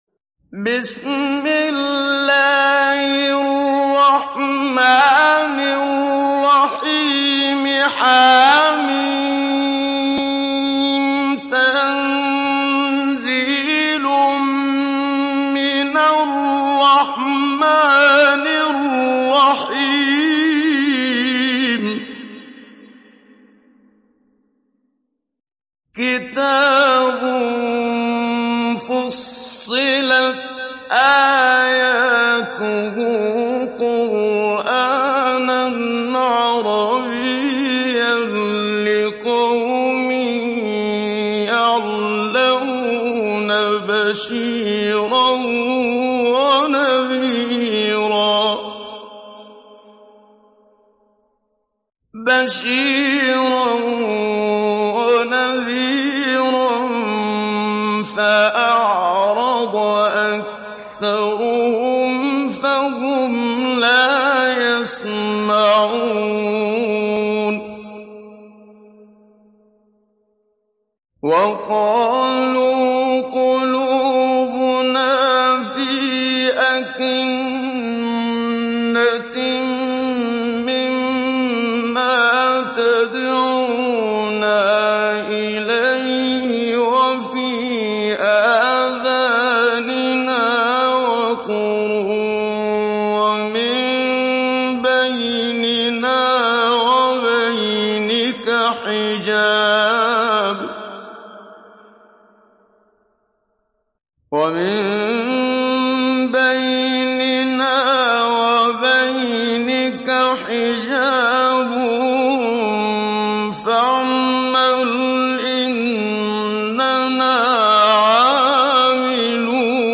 Download Surat Fussilat Muhammad Siddiq Minshawi Mujawwad